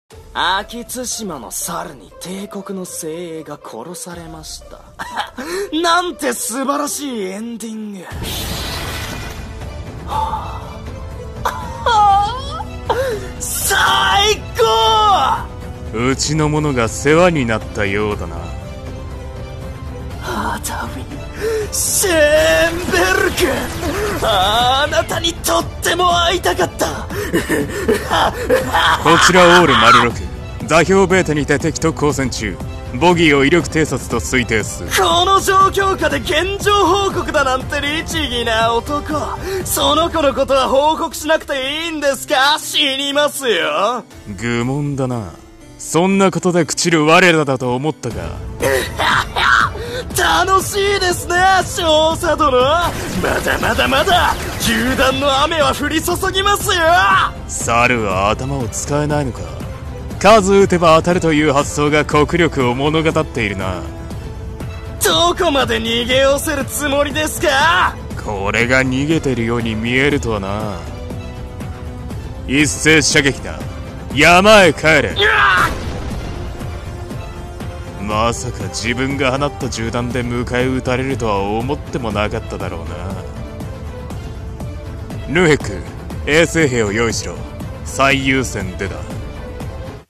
【声劇】一騎当千の護り